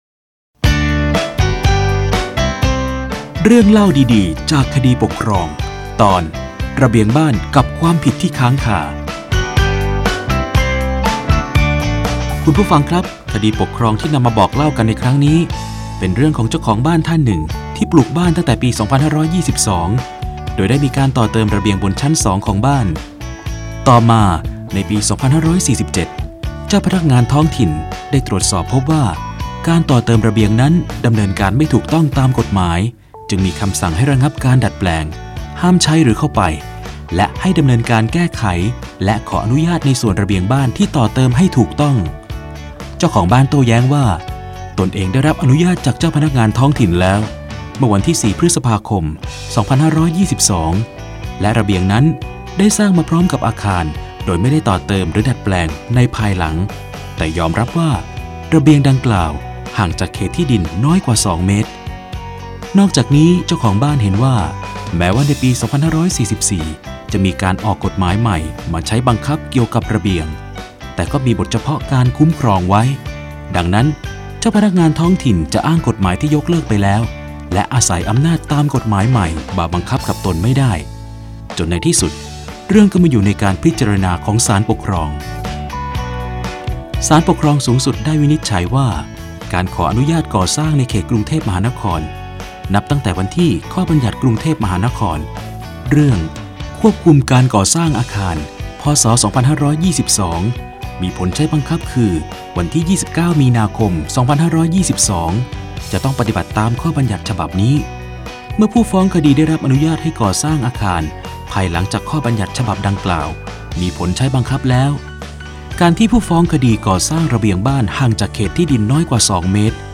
สารคดีวิทยุ ชุด เรื่องเล่าดีดีจากคดีปกครอง - ระเบียงบ้านกับความผิดที่ค้างคา
ลักษณะของสื่อ :   กรณีศึกษา, คลิปเสียง